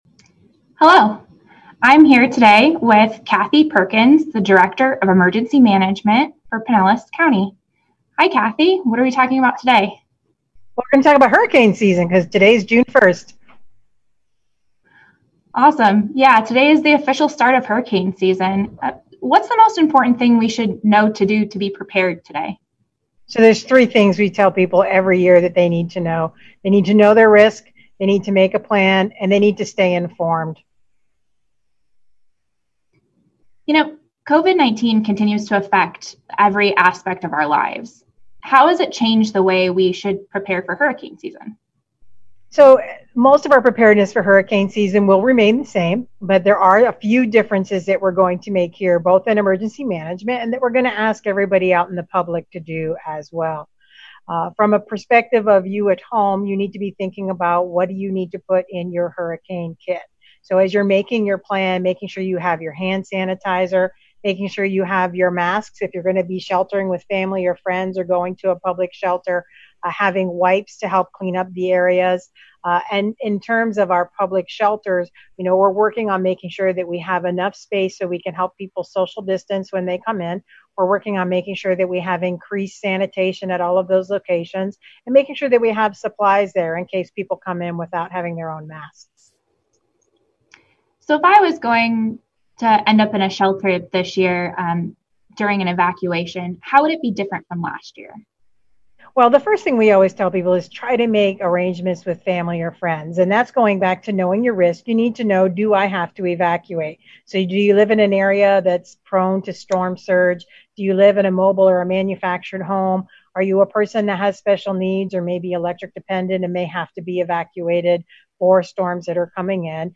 Pinellas County Emergency Management Director Cathie Perkins on Facebook Live. Learn about how you can prepare for Hurricane season, the best ways to stay informed and much more.